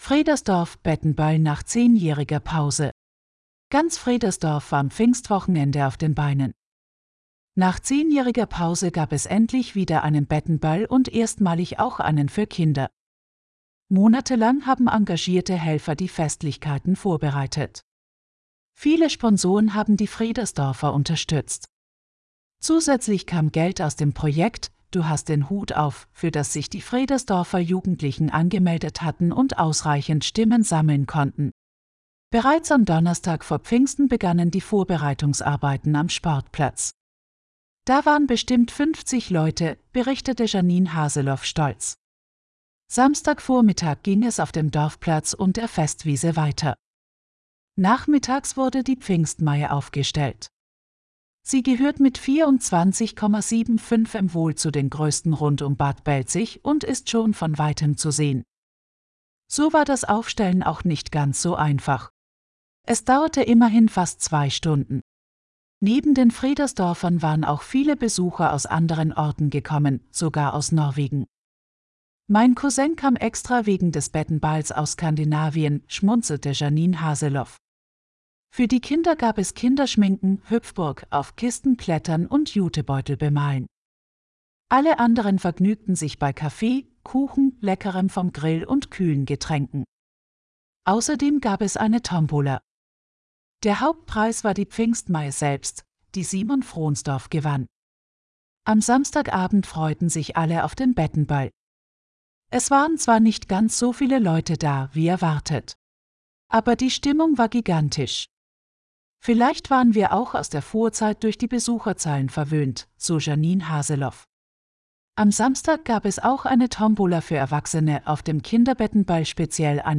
Ab sofort können Sie einige unserer Artikel auch hören. Eine KI macht es möglich.